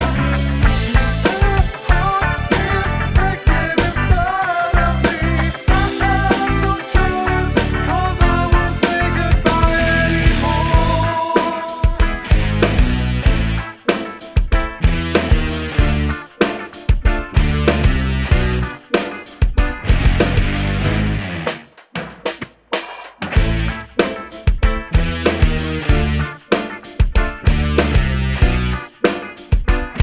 Three Semitones Down Pop (2000s) 3:32 Buy £1.50